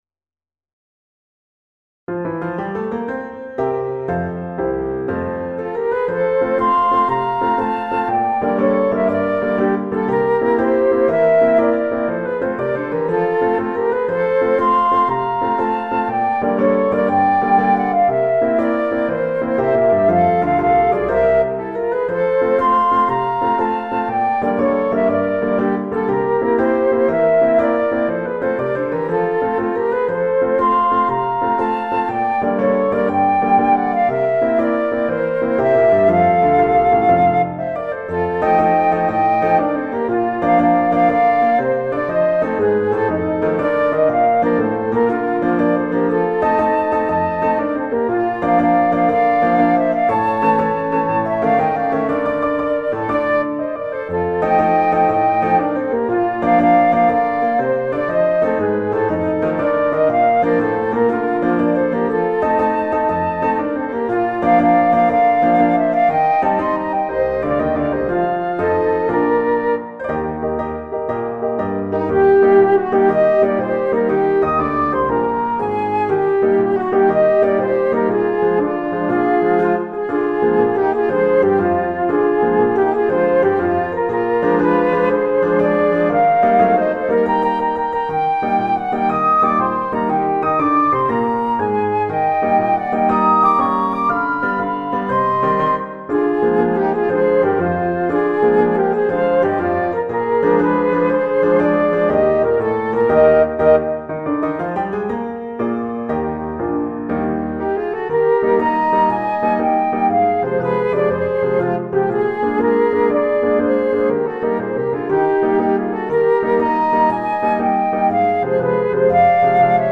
A bright 6/8 march for Flute Duet with Piano Accompaniment.